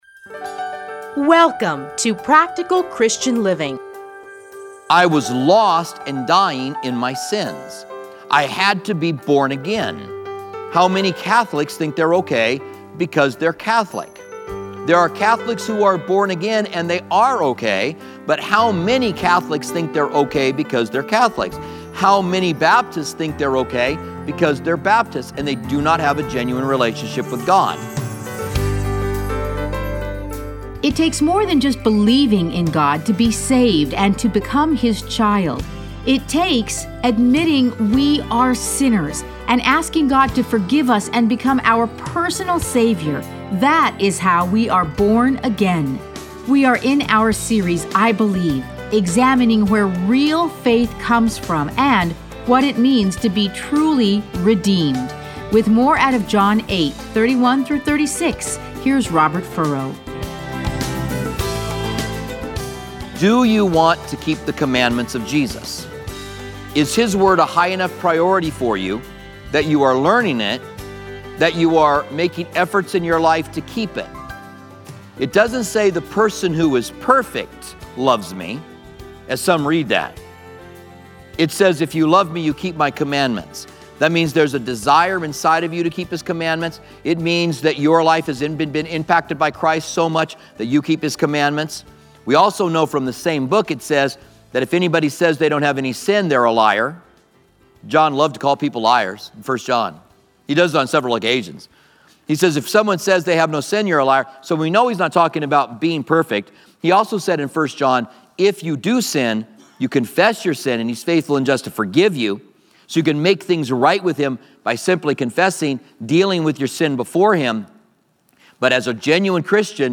Listen to a teaching from John 8:37-59.